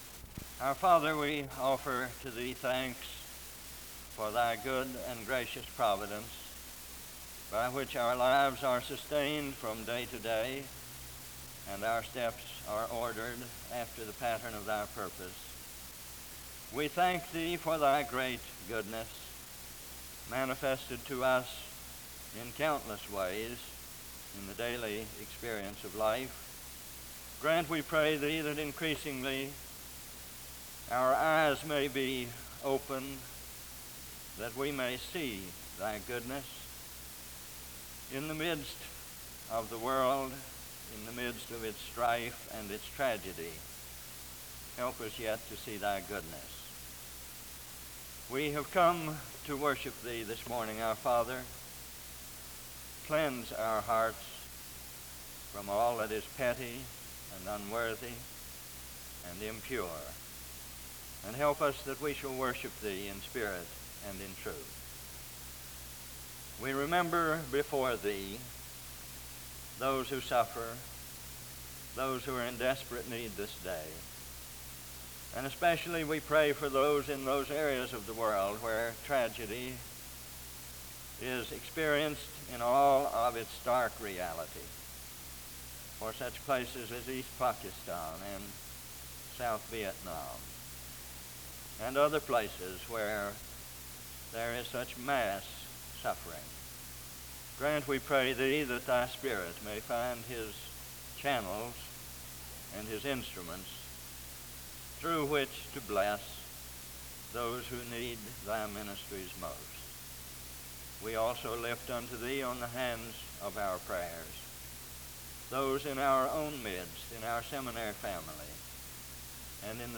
The service begins with a prayer (0:00-2:28).
The service continues with a period of singing (4:06-6:31).